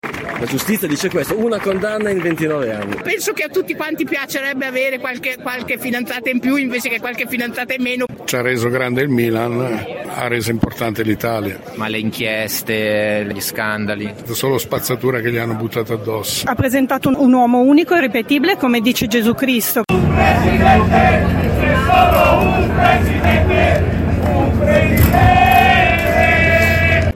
Una signora canta “menomale che Silvio c’era” riprendendo la celebre canzone pro Berlusconi di qualche anno fa. Gli ultras della Curva Sud del Milan cantano “un presidente c’è solo un presidente”, hanno portato i bandieroni di oggi e di ieri, tra cui quello storico con la scritta Baresi e il numero 6 della maglia simbolo dell’epoca delle vittorie berlusconiane